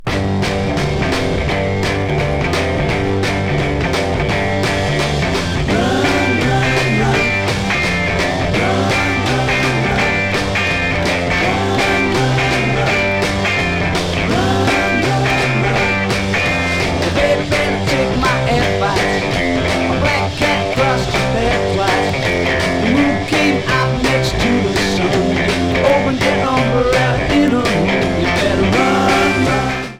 Mostly true stereo